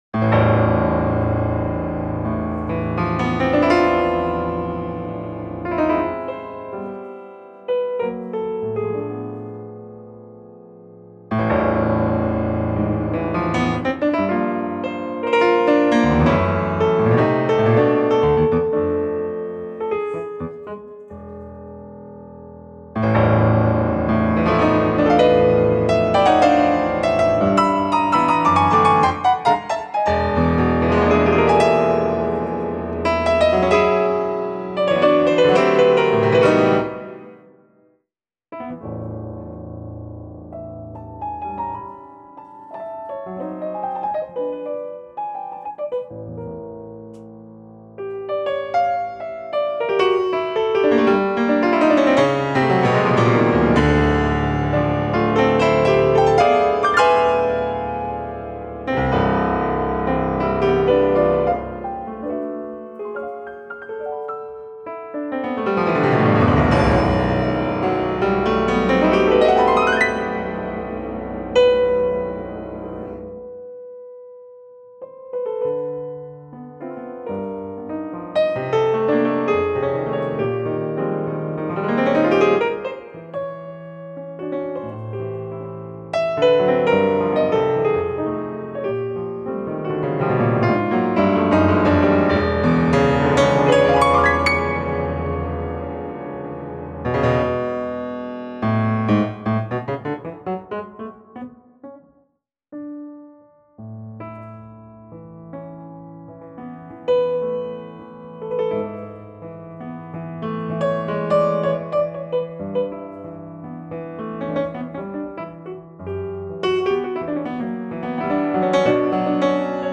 piano
guitar
bass
violin
bandoneon